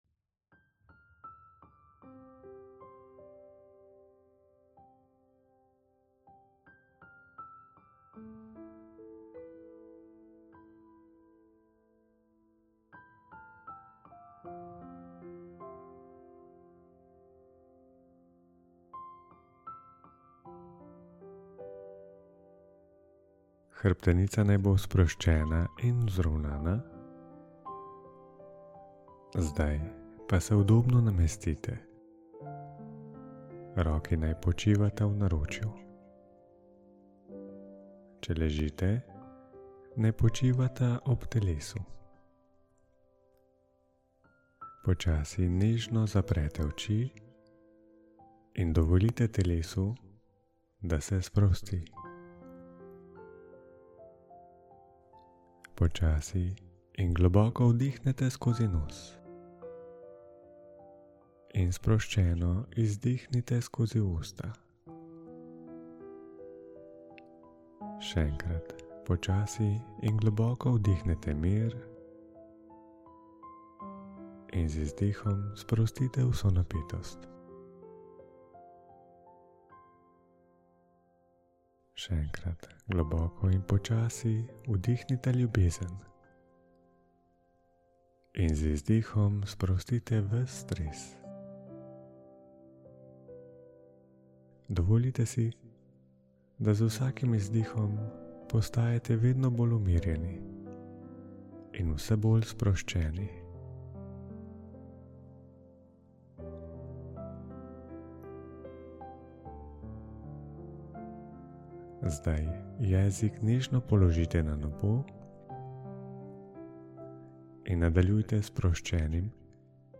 * * * * * Ta 20-minutna vodena refleksija ti bo pomagala ozavestiti ključna področja življenja, za katera si lahko resnično hvaležen/-a. 📩 Dodatna navodila za uporabo in priporočila te čakajo v tvojem e-nabiralniku.